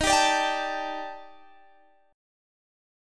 Listen to EbM7b5 strummed